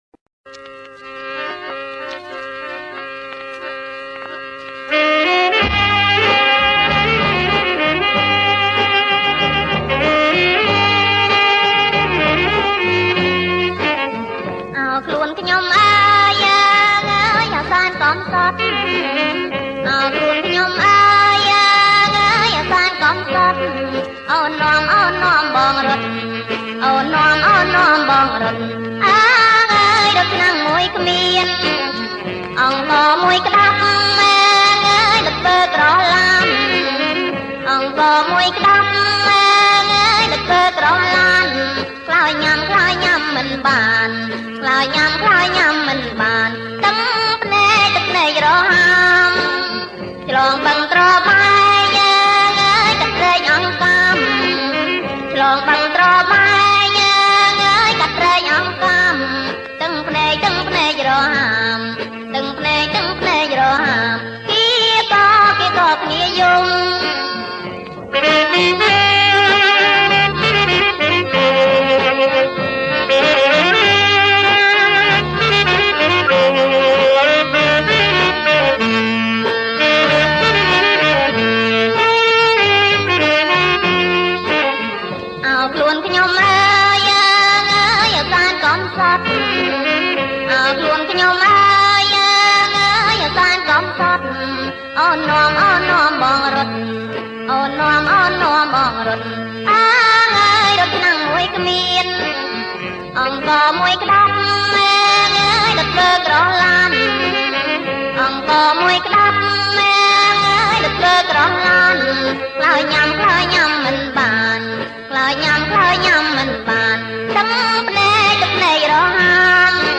• ប្រគំជាចង្វាក់ សារ៉ាវ៉ាន់